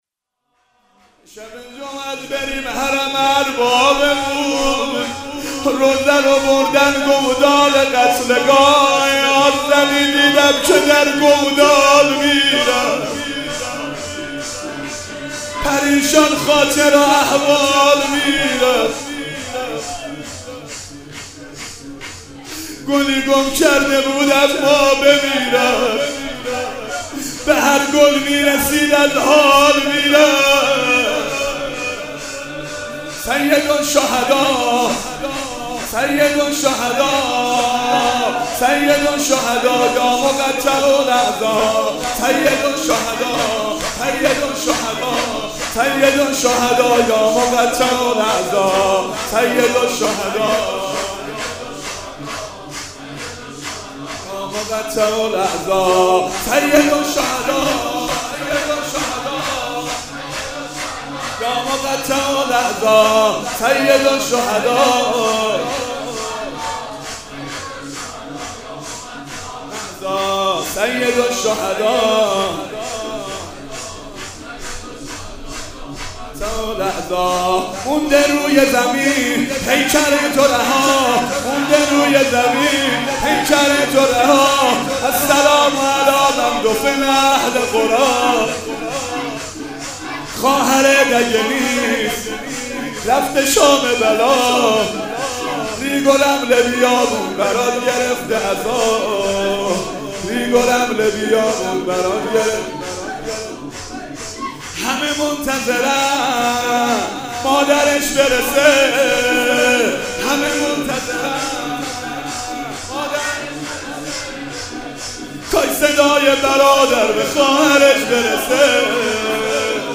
مناسبت : شب بیست و هشتم رمضان
قالب : شور